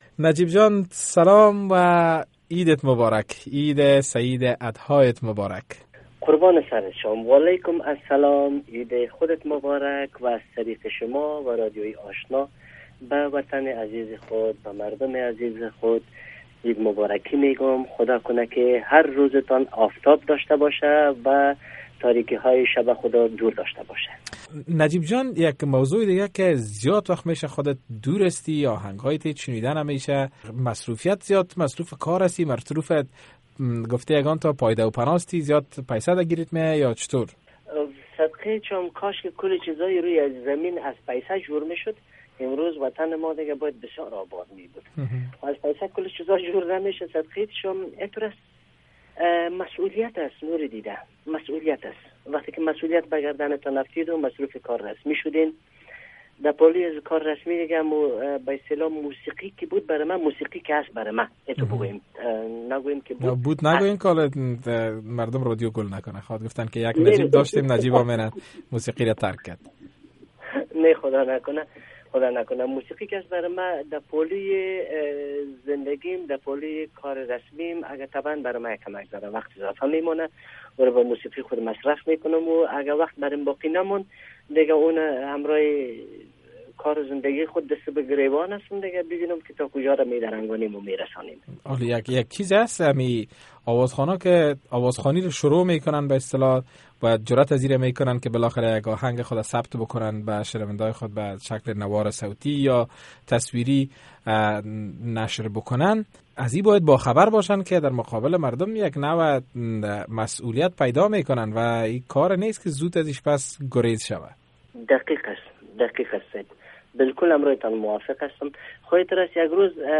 شرح بیشتر مصاحبه